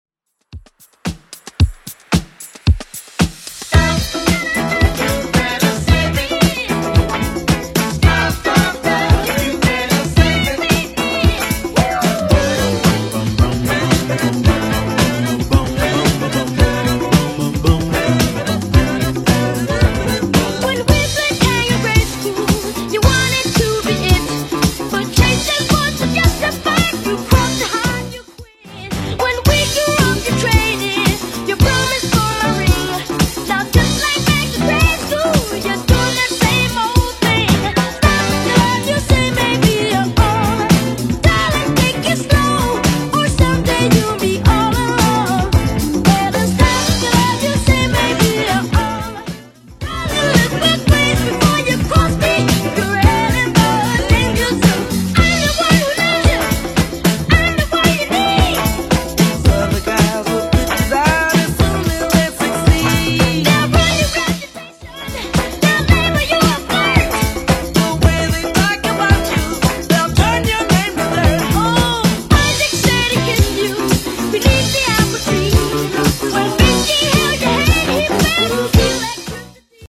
Genre: 2000's
BPM: 123